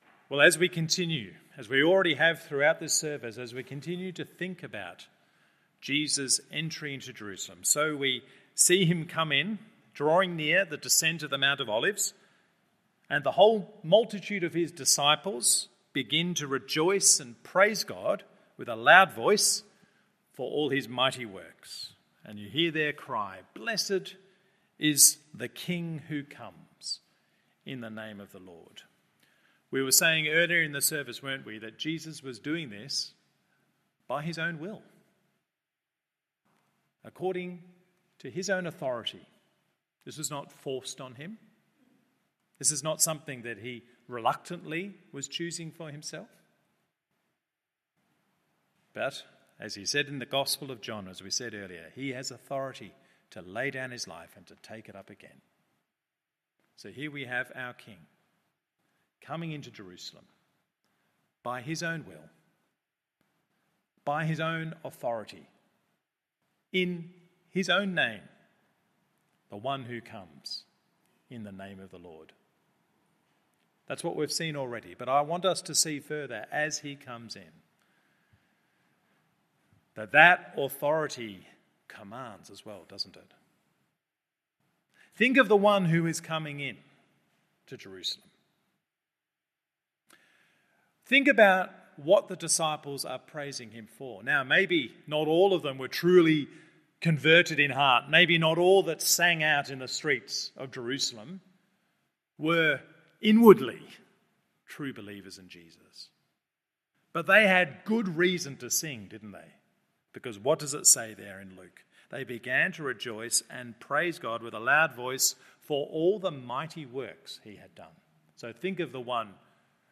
Morning Service Luke 19:37-44…